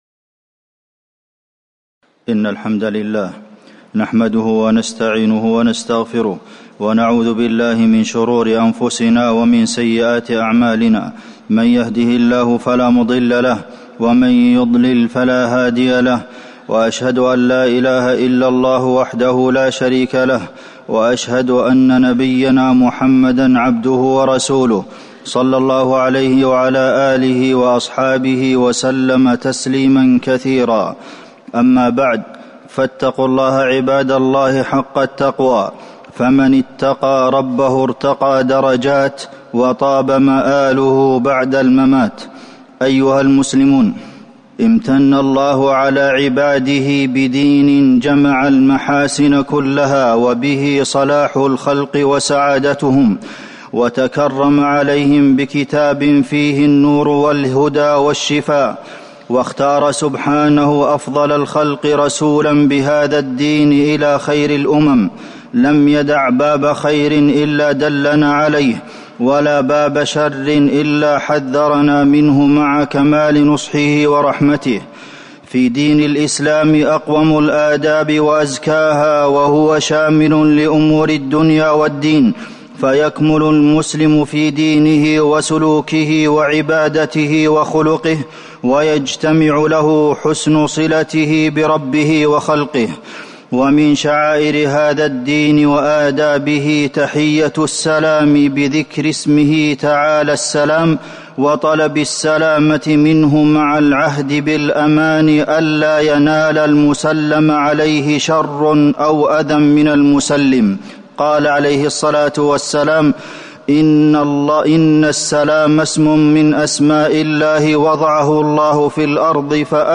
تاريخ النشر ٢٥ ربيع الأول ١٤٤١ هـ المكان: المسجد النبوي الشيخ: فضيلة الشيخ د. عبدالمحسن بن محمد القاسم فضيلة الشيخ د. عبدالمحسن بن محمد القاسم فضل السلام وآدابه